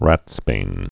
(rătsbān)